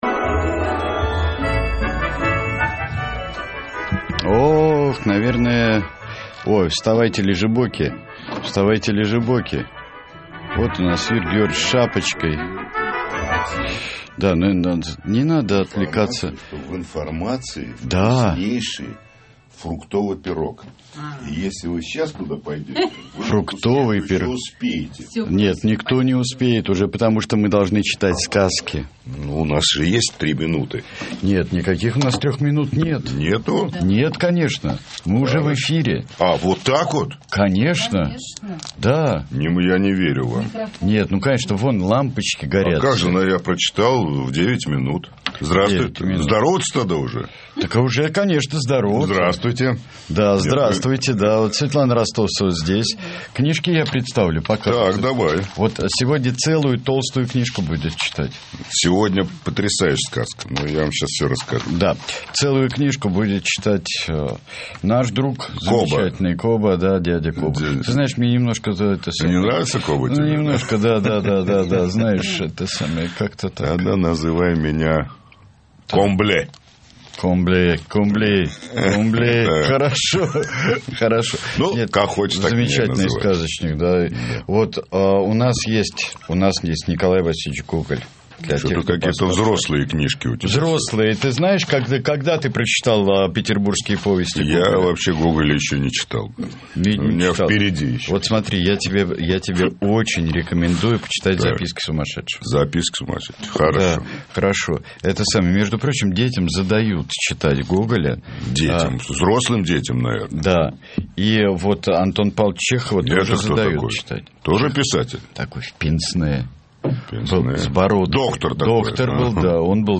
Юрий Кобаладзе читает грузинские сказки - Юрий Кобаладзе - Детская площадка с папашей Бунтманом - 2015-07-19